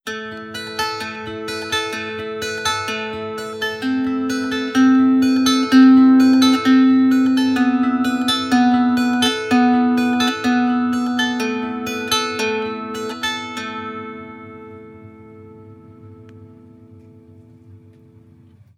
Original creative-commons licensed sounds for DJ's and music producers, recorded with high quality studio microphones.
meerklang leier scale sequence.wav
meerklang_leier_scale_sequence_Hg3.wav